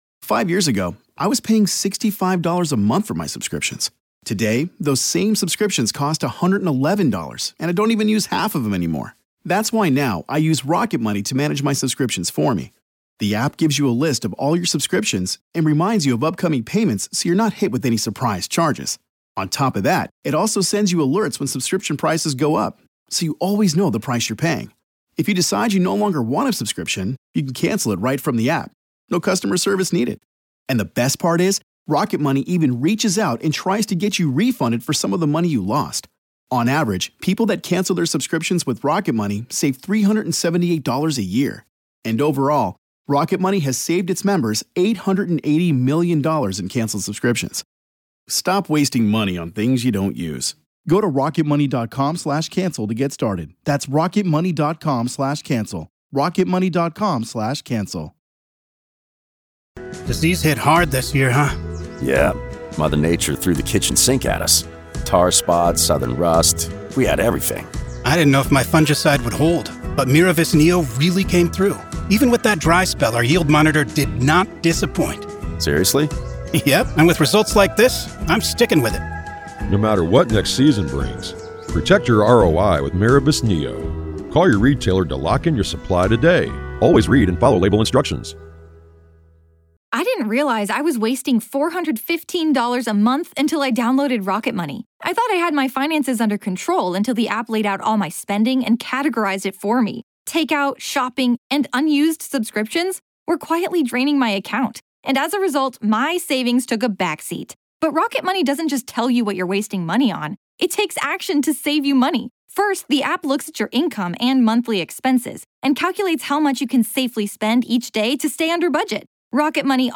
A gripping courtroom drama unfolds